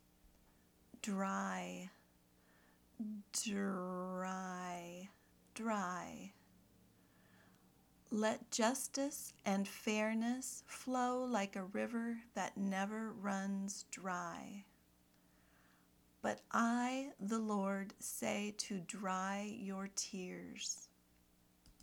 /draɪ/ (adjective)